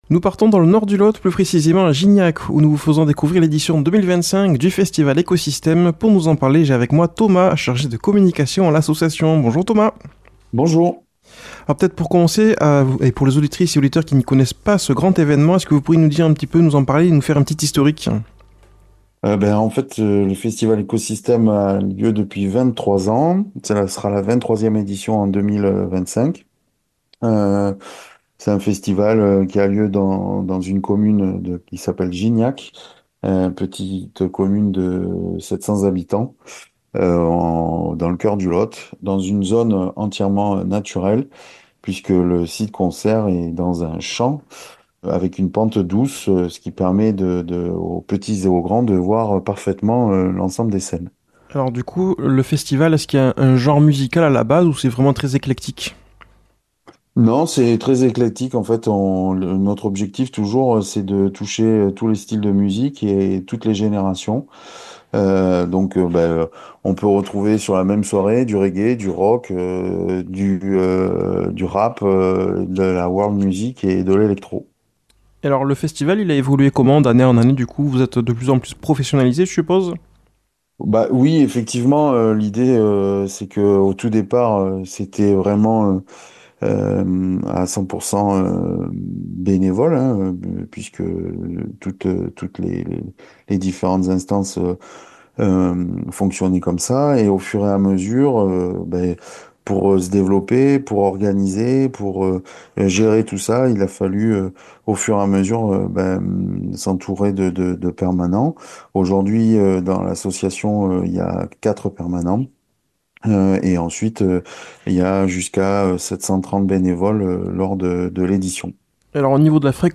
a comme invité par liaison Zomm
Présentateur